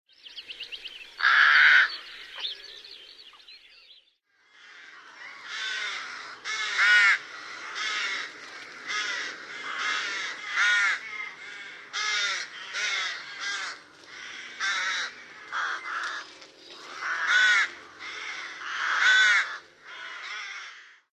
Mustavaris
Kuuntele mustavariksen ääntä.